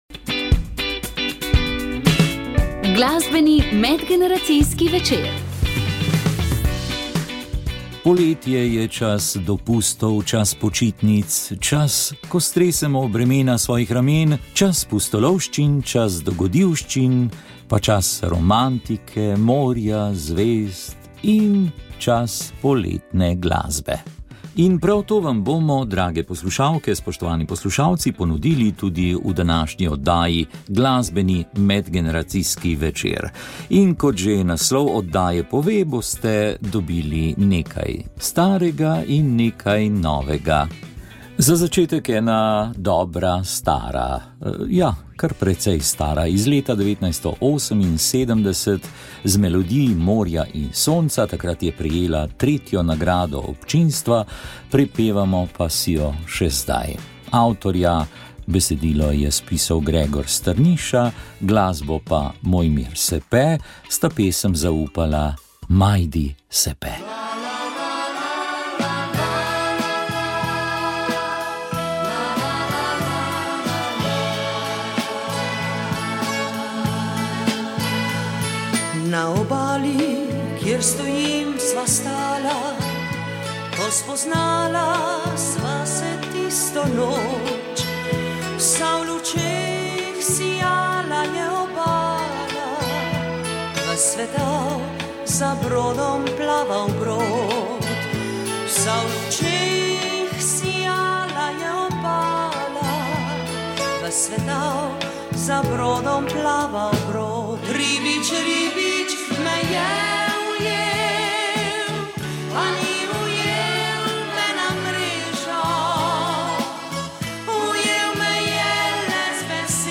Osrednje mesto v nedeljski kmetijski oddaji smo namenili perečemu vprašanju spreminjanja modela določitve bonitete kmetijskih in gozdnih zemljišč. Za vas smo pred mikrofon povabili predstavnike Urada za nepremičnine na Geodetski upravi Republike Slovenije. V oddaji pa tudi o dogajanju povezanem s kanalom C0.